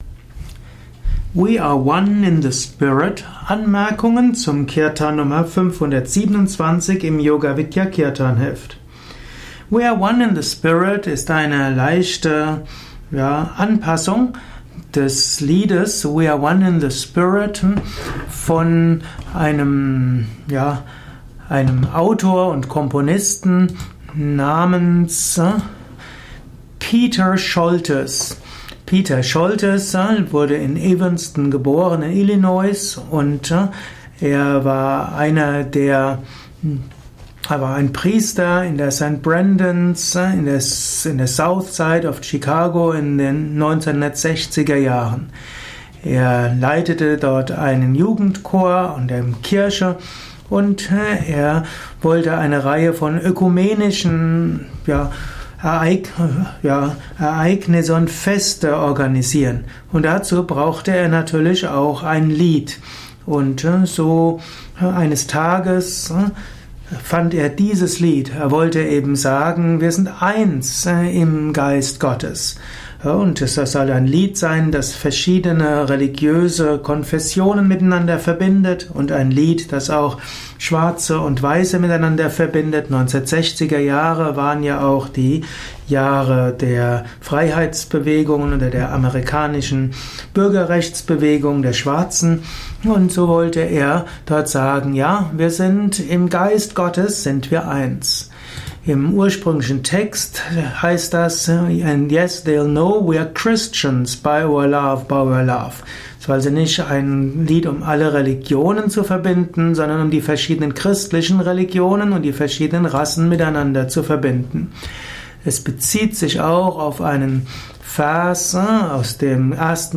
Audio mp3 Erläuterungen